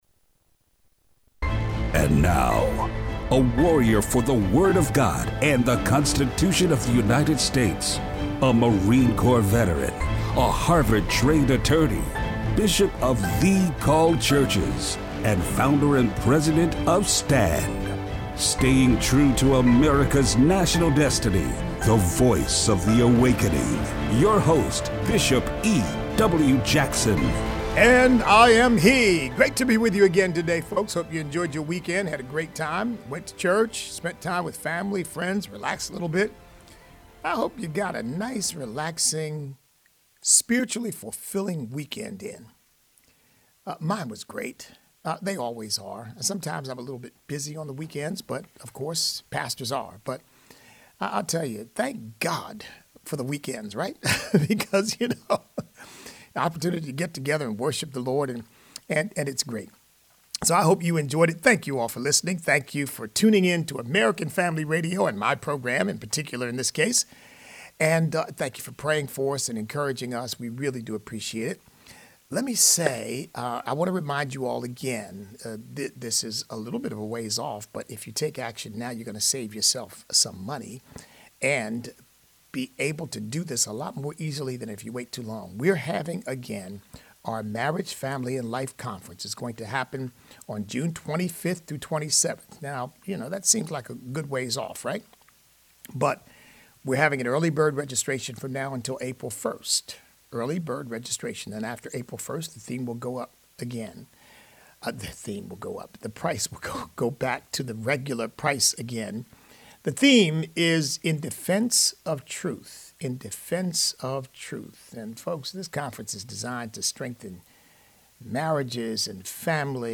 No bail for criminals in New York is causing big problems. Listener call-in.